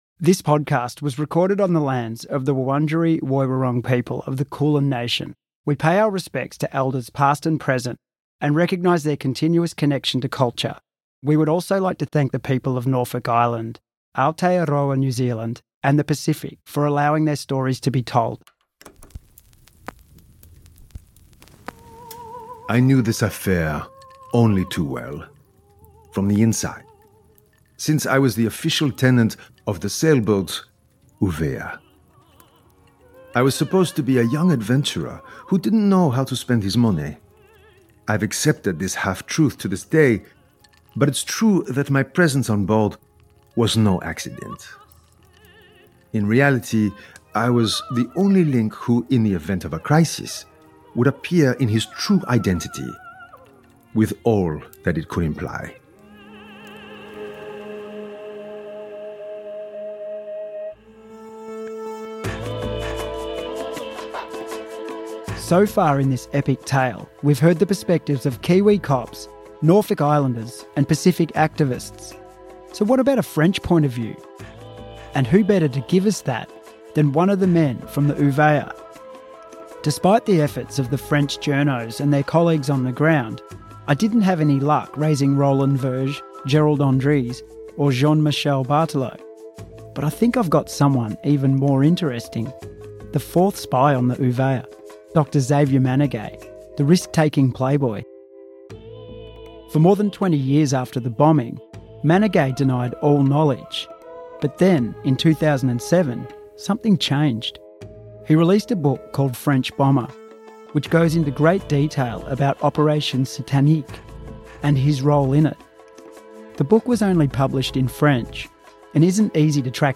In our final episode, we hear from one of the spies in his own words, and of the many lives he lived. New details of suspected police informants and political cover ups are unveiled. And Kiwi detectives rue an opportunity lost.